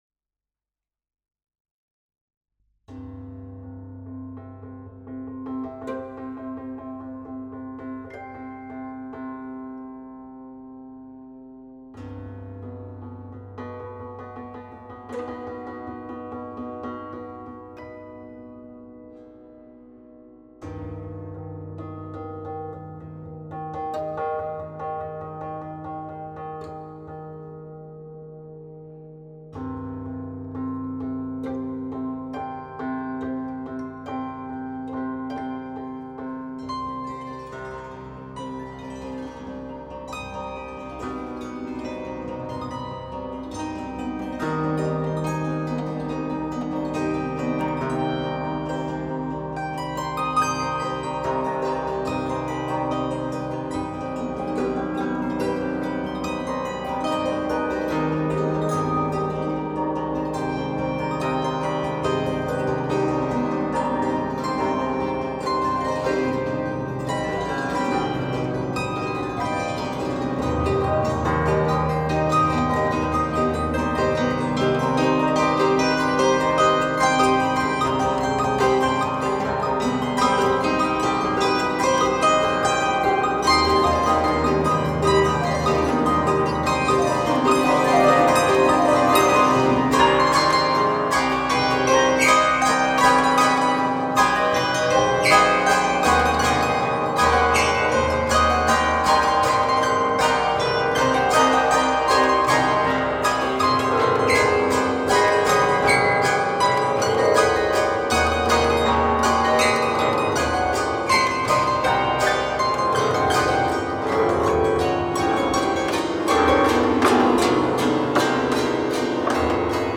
5 KOKLES